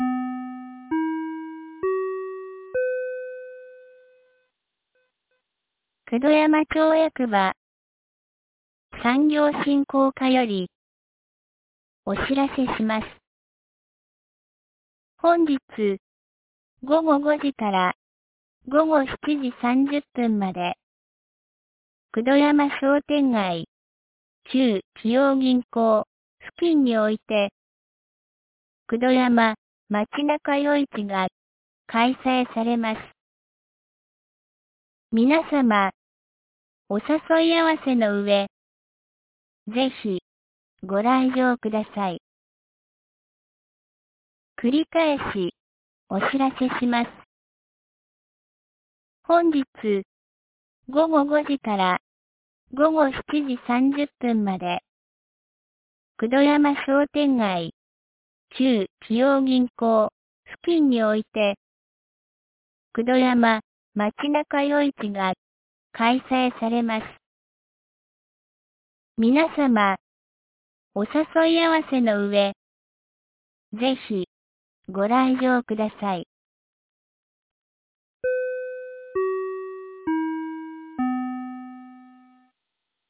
2024年08月18日 10時06分に、九度山町より全地区へ放送がありました。
放送音声